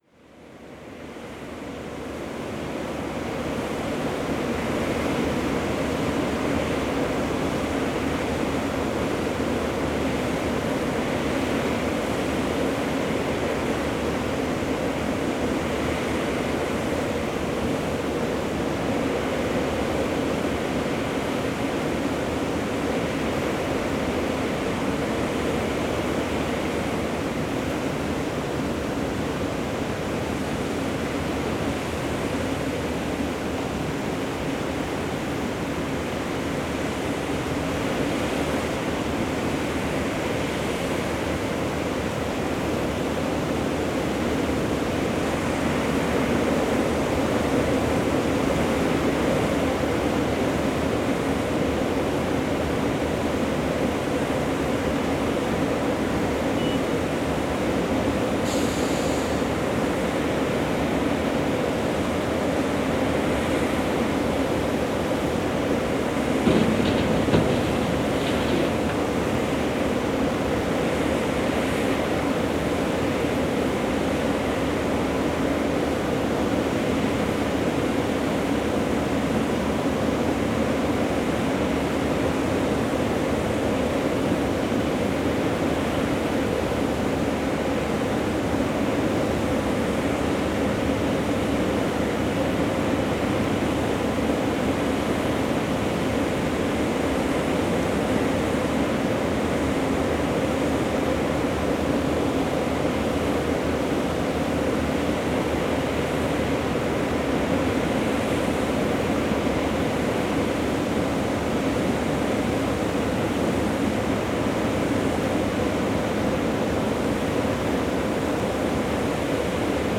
Paisagem sonora de obras de construção de mini-hídrica no rio Vouga junto à Ponte Moreno em Galifonge, Lordosa a 25 Fevereiro 2016.
Num dia soalheiro na margem direita do rio Vouga junto à Ponte Moreno encontrámos uma paisagem sonora inesperada.